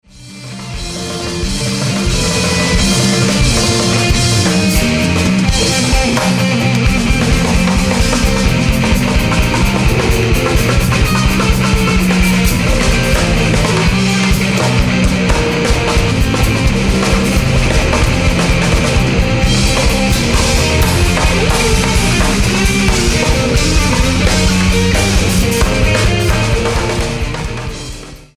２１日のボイスレコーダー回収。
割とあばれ。若干つかみ所が少なかったな。スキマが少なかった。ハリキリ過ぎてたっぽい感じ。これはこれでバンドのシチュエーションを反映していて面白い。
本番。ギター・ベース共にセミアコの為か、常にハウリング気味でモコモコいってモニターから聞こえる音もジョワジョワ。
ジャズロックでインプロビゼーション多いからメンバーの音聞こえないとやりづらい。
大学祭。